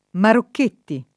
marokk%tti] e Marochetti [marok%tti] cogn. — nell’una e nell’altra forma i cogn. dei patrioti Giovanni Battista (1772-1851) e Giuseppe Felice (1804-66) e dello scultore Carlo (1805-67)